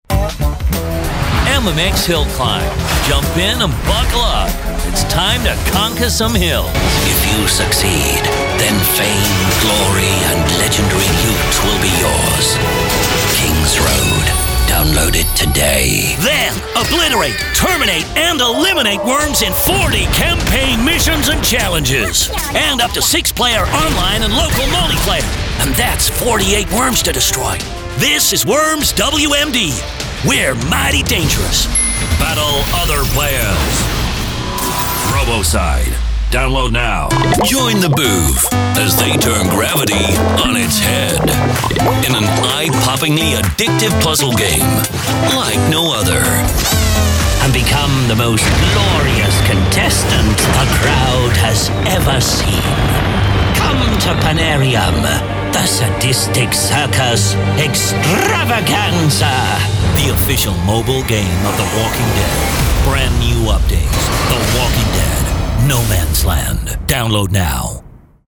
Game Trailer Voice
From deep Movie Trailer voices to characters with mystical deliveries, you got it.
With the last 4 Worms games under my belt, I was back again voicing more characters for the next legendary game.